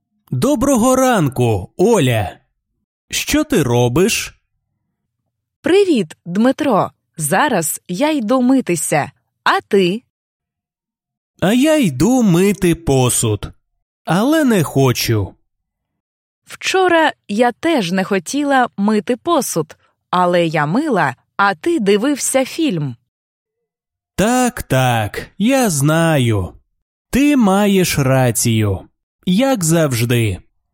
Dialogues
basic-ukrainian-lesson-07-dialogue-01.mp3